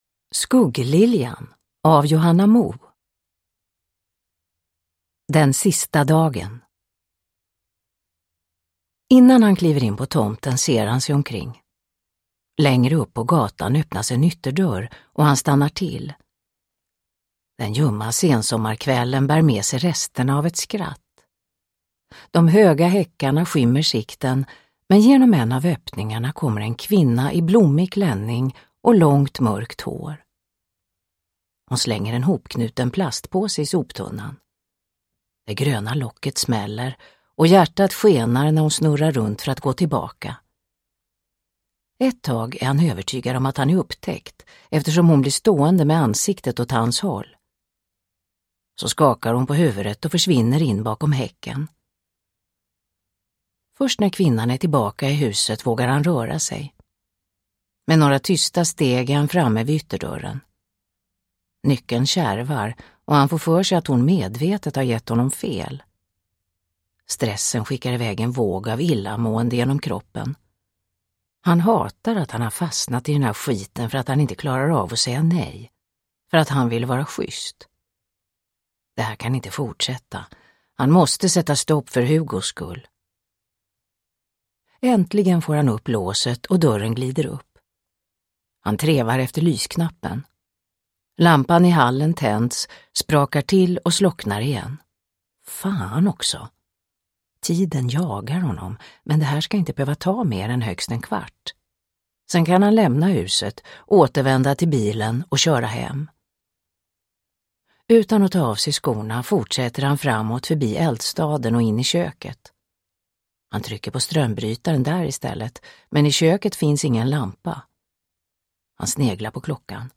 Skuggliljan – Ljudbok – Laddas ner
Uppläsare: Marie Richardson